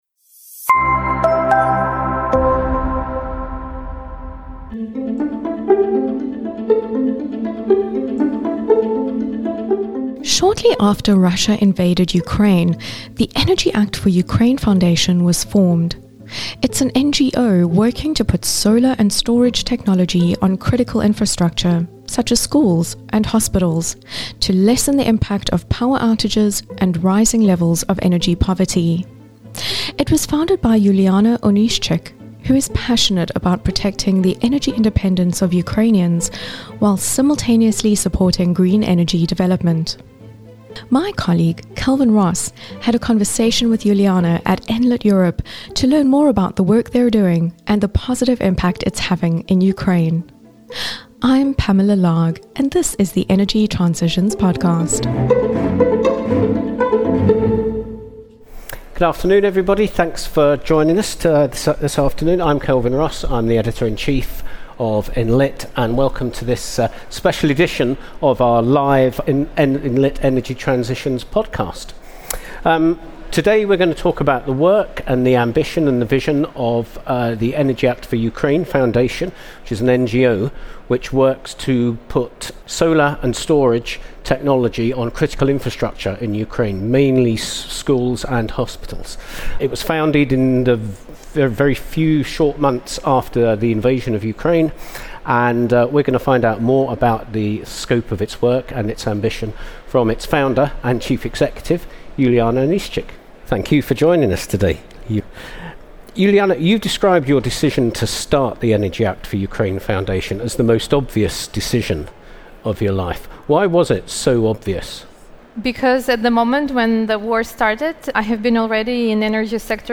in front of a live audience at Enlit Europe in Milan